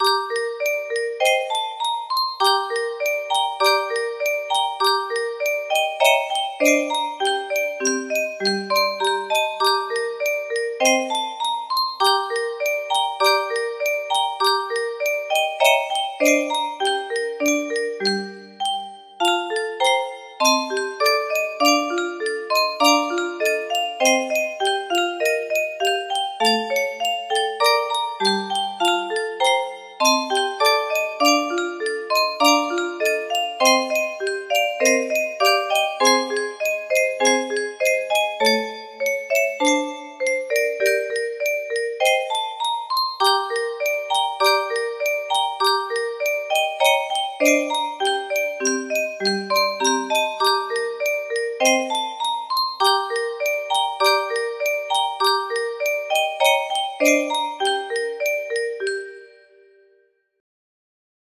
Tchaikovsky - Swan Lake music box melody